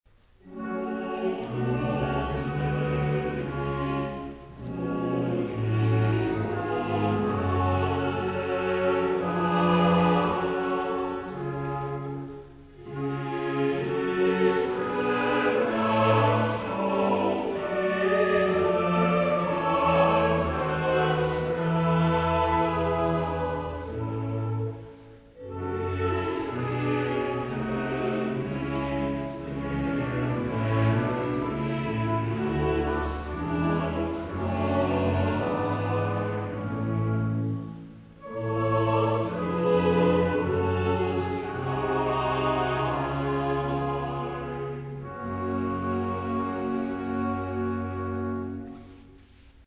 Es handelt sich hierbei allerdings teilweise um nicht professionell erstellte Aufnahmen.
„Johann Sebastian Bachs Johannes-Passion (BWV 245) in authentischer Besetzungsstärke“ – Aufführung am 17.
Das Konzert war Teil einer in Hannover und im weiteren Umland einmaligen Konzertreihe, in der Meisterwerke der Kirchenmusik des Barocks nicht nur mit „alten“ Instrumenten und in historisch orientierter Spielweise, sondern auch in authentischer Besetzung in Hinblick auf die vokalen Teile (Solostimmen auch für die „Chorpartien“!) aufgeführt werden.